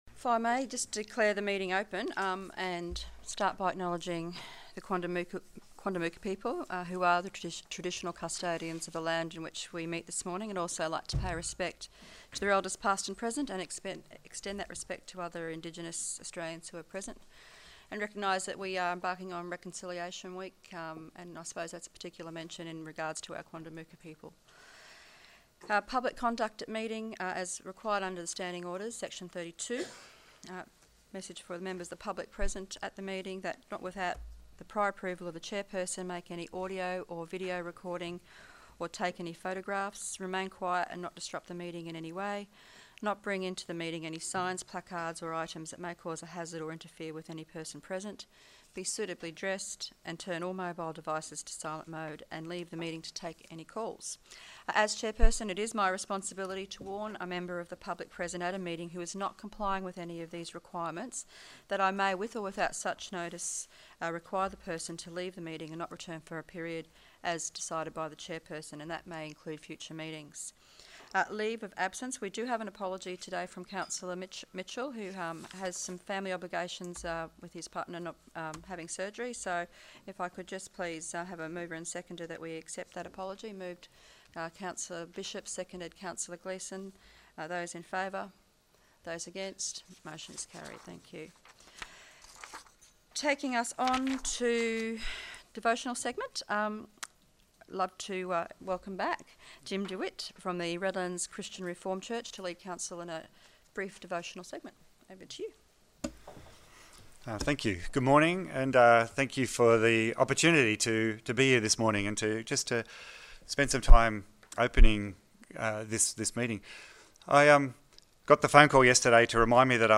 General Meeting Audio - 24 May 2017